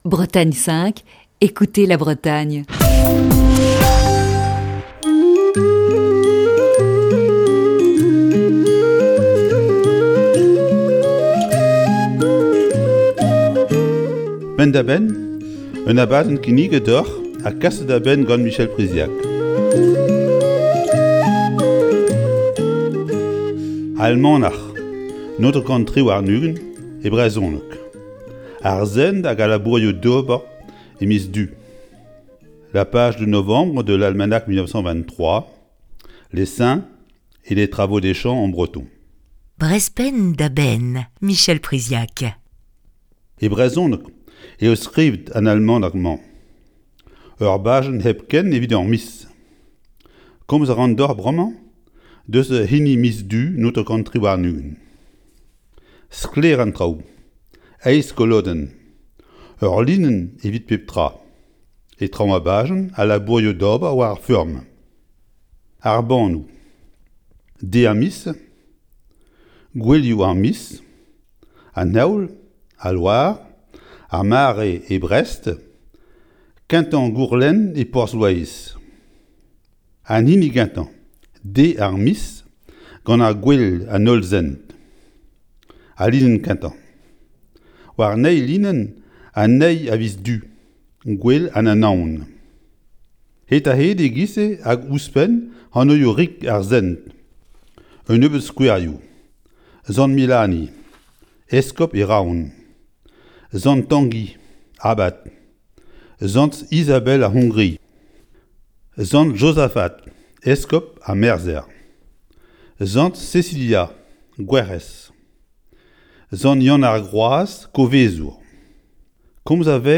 Chronique du 8 décembre 2020. En un temps où les applications et autres réseaux n'existaient pas, l'almanach était bien souvent le seul moyen pour connaître les éphémérides et avoir des conseils pour les travaux des champs.